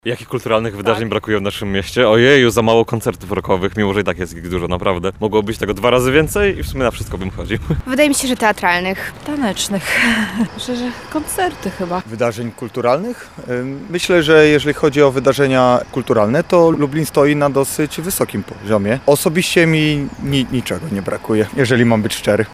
[SONDA] Jakich wydarzeń kulturalnych brakuje w Lublinie?
Zapytaliśmy, więc mieszkańców Lublina, jakich wydarzeń kulturalnych brakuje w mieście:
sonda-wydarzenia-kulturalne.mp3